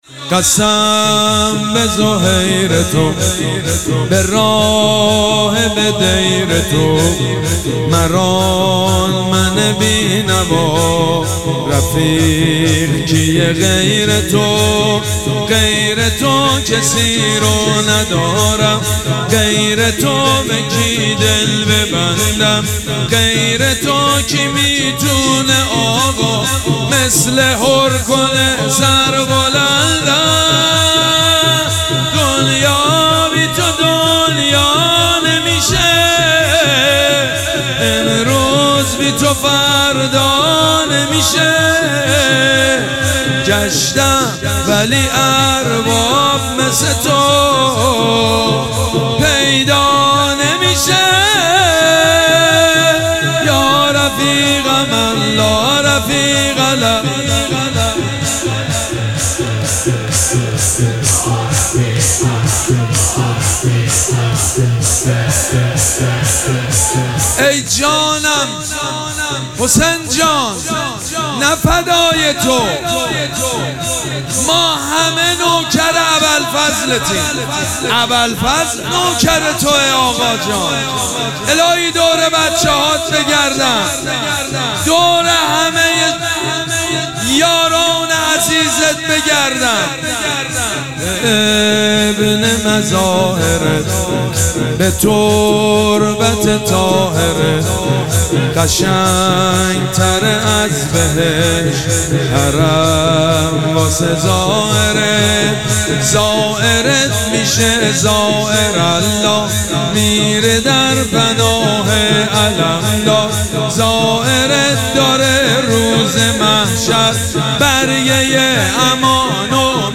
شب سوم مراسم عزاداری اربعین حسینی ۱۴۴۷
شور
حاج سید مجید بنی فاطمه